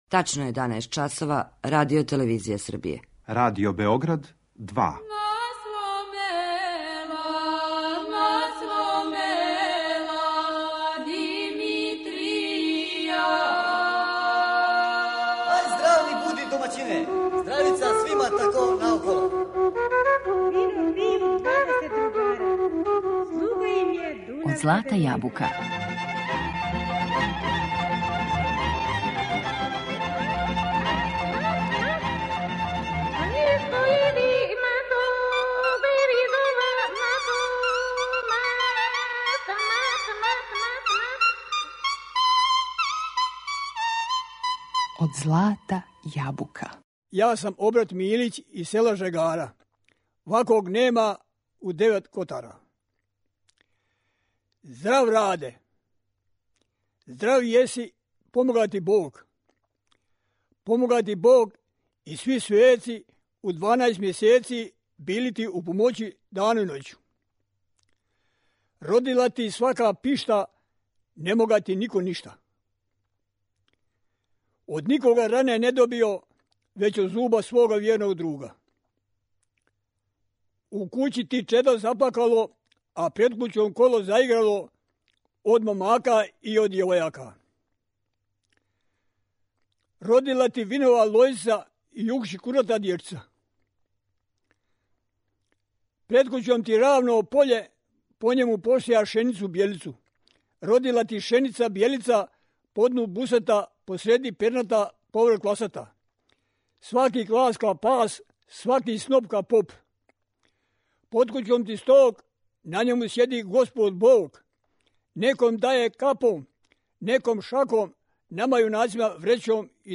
Традиционално пјевање, гуслање, свирка на диплама и приповедање